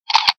camera.mp3